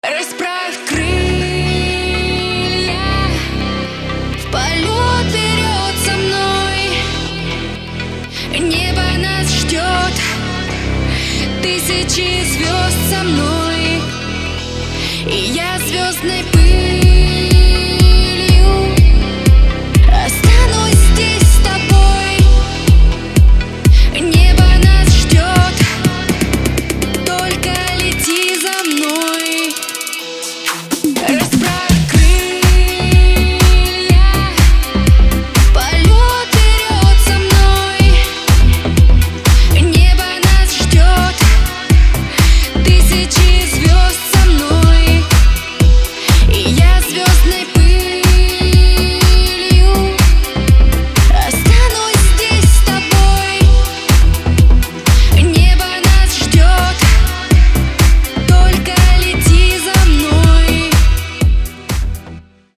• Качество: 256, Stereo
женский вокал
dance
Electronic
Club House
электронная музыка
спокойные